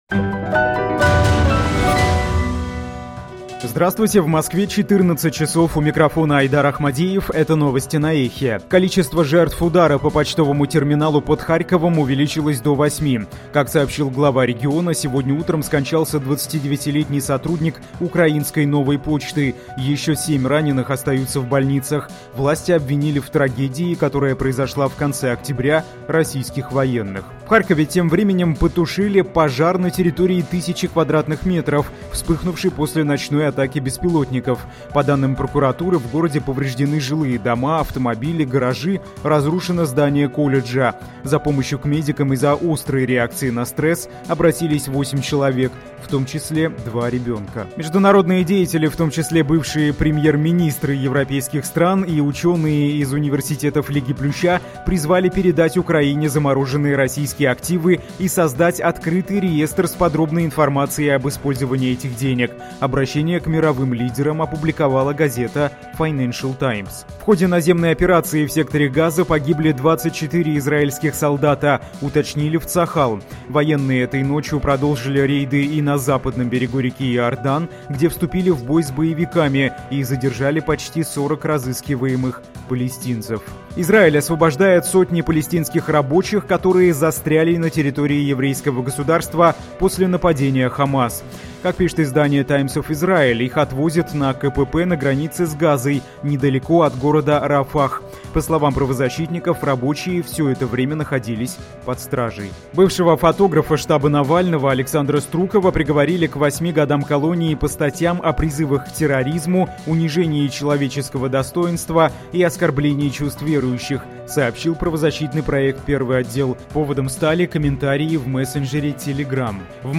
Слушайте свежий выпуск новостей «Эха».
Новости